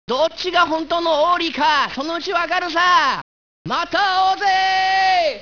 Nome: Arsène Lupin III (la pronuncia giapponese di Lupin è Rupan)
breve sonoro